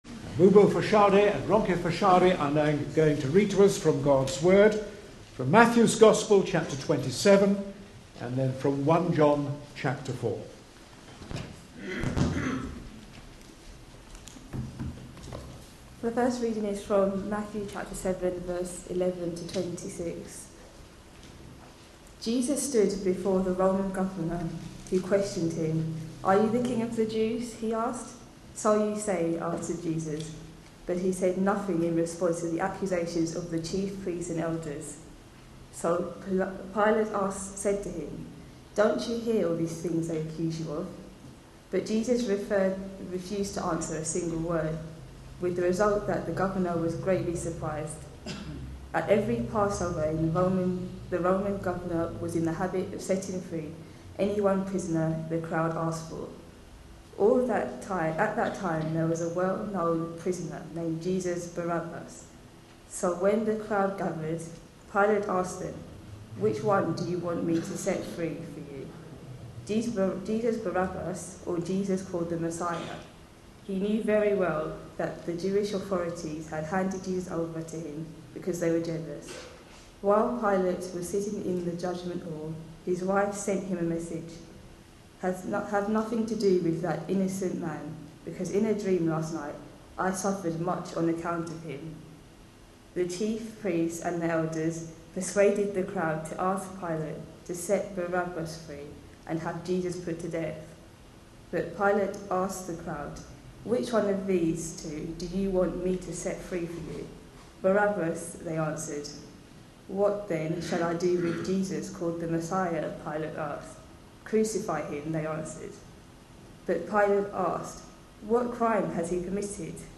A sermon preached on 10th March, 2013, as part of our Passion Profiles and Places -- Lent 2013. series.